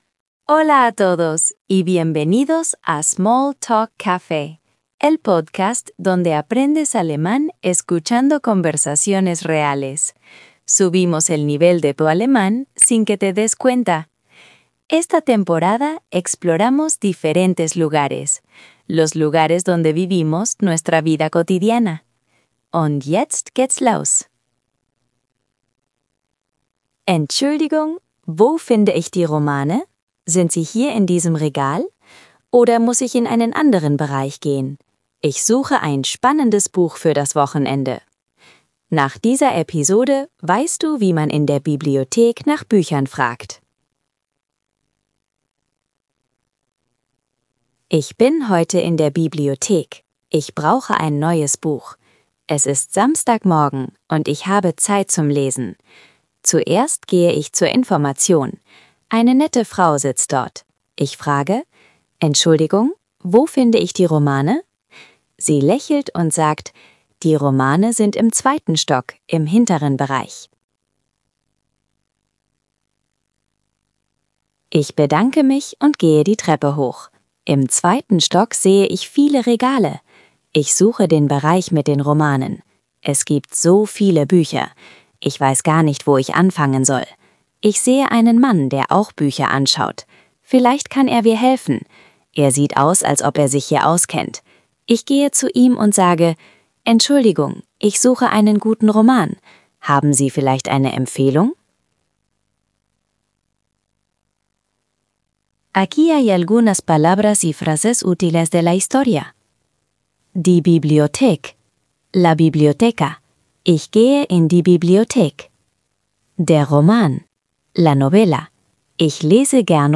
Keywords: podcast sobre alemán, aprender alemán, alemán fácil, alemán para principiantes, cómo preguntar por libros en alemán, vocabulario alemán biblioteca, alemán A1, alemán A2, alemán nivel básico, práctica auditiva alemán, podcast de inmersión, formato storytelling alemán, Small Talk Café, Small Talk Café podcast, Bibliothek, Bücher, Romane, Autor, Regal, Ausleihen, Empfehlung, Deutsch lernen, Anfänger Deutsch, Hörverstehen, Alltagsdeutsch